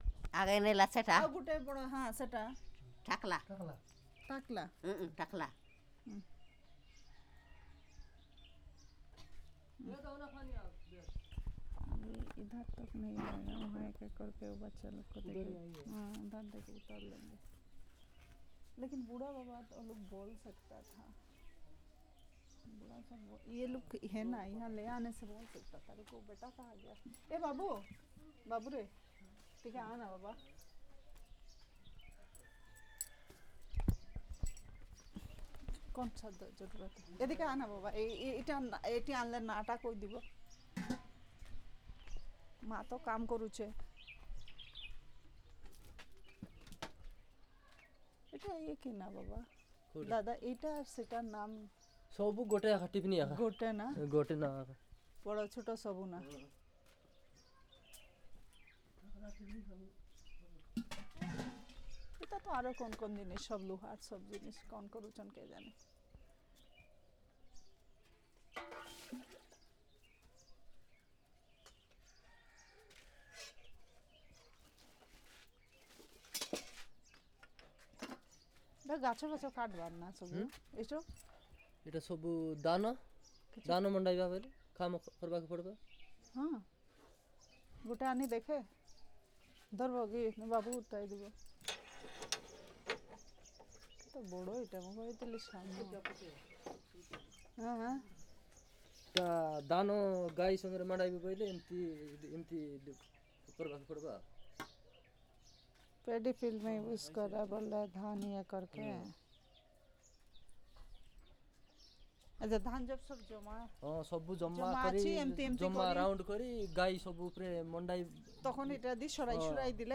Elicitation of words about general things